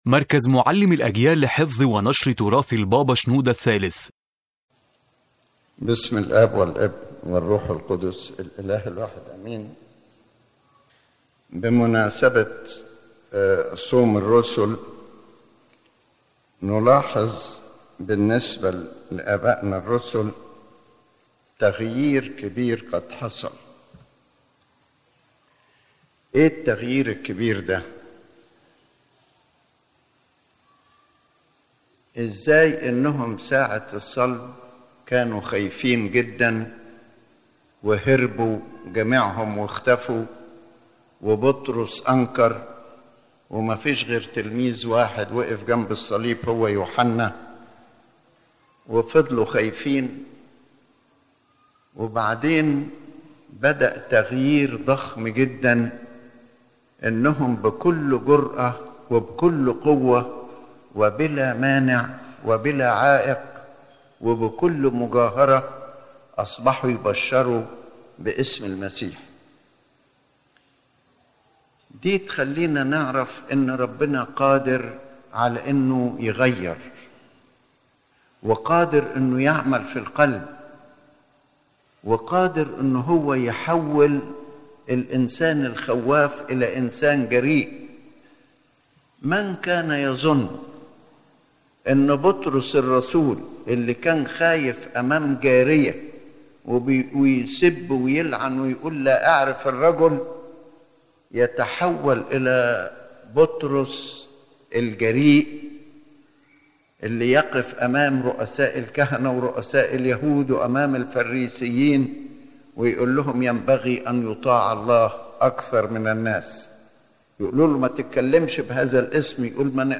The Main Idea of the Lecture